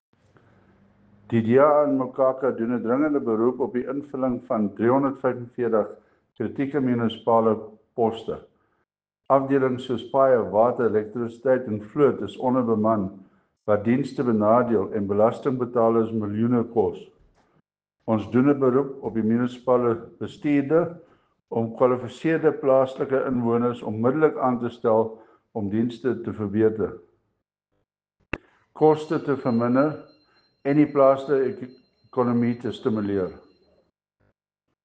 Afrikaans soundbites by Cllr Chris Dalton and